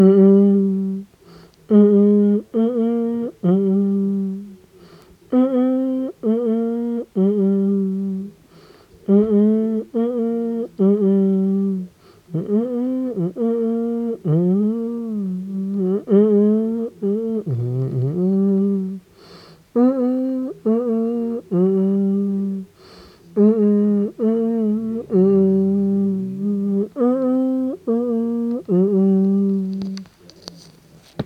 Je suis à la recherche d’une musique entendue sur Skyrock : /uploads/default/original/3X/9/b/9bbdbcf5b3b08c29ea57fa2f9f0b815f9645a62b.mp3